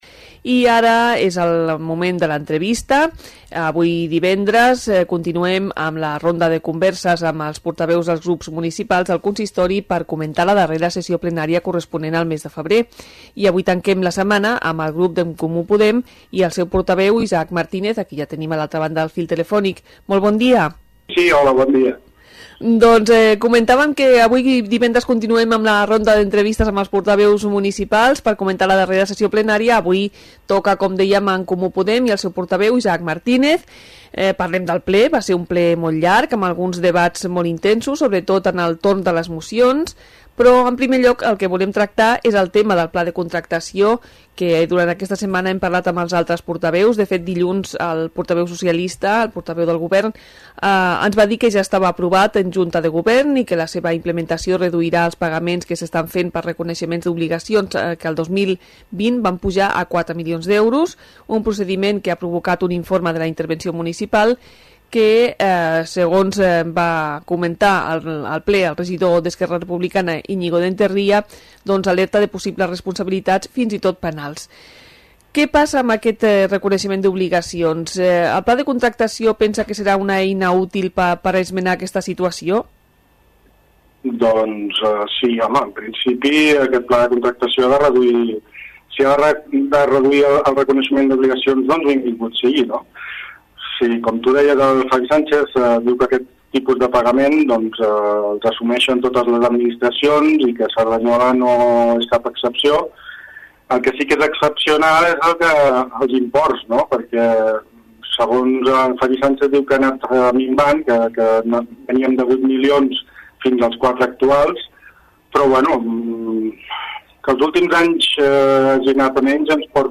Entrevista-Isaac-Martínez-ECP-Ple-febrer.mp3